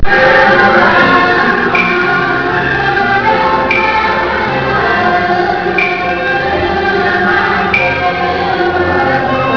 Colorful Caodaist temple, with their music
Caodaist temple, Tay Ninh, Vietnam